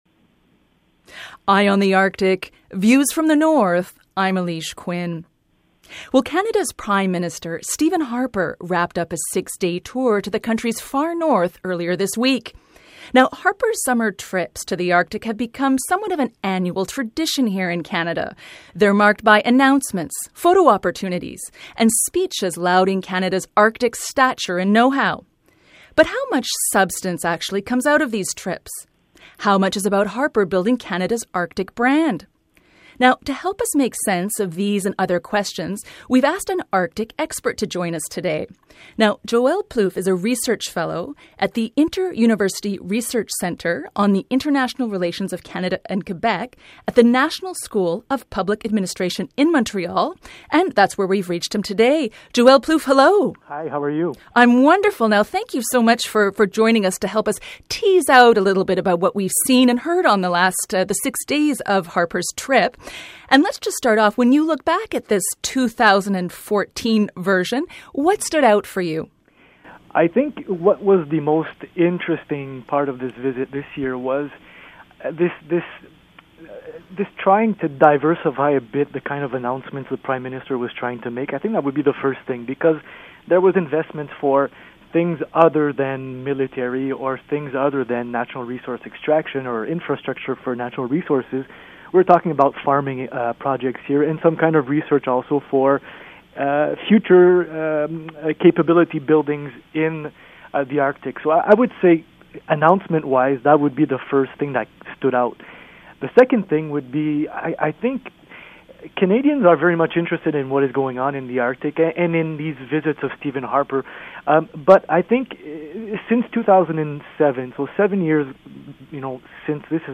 Feature Interview: What Prime Minister’s northern tours tell us about Canada’s shifting Arctic priorities